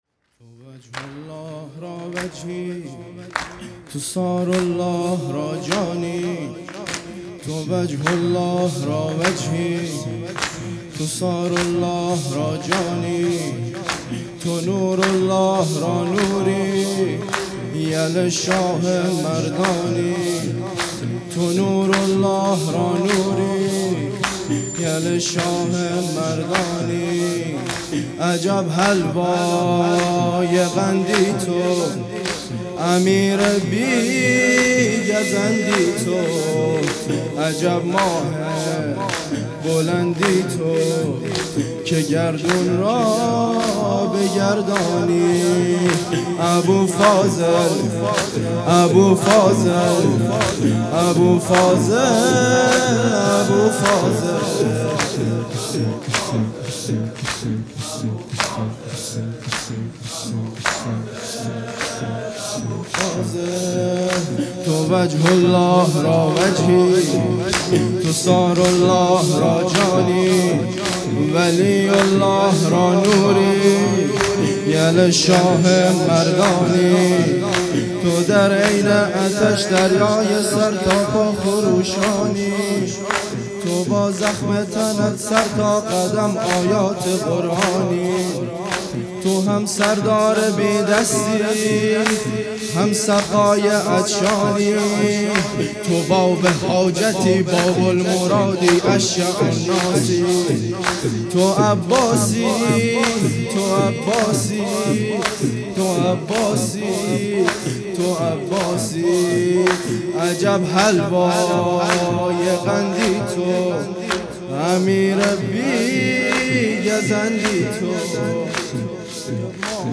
جلسه هفتگی ۸ اسفند ۹۶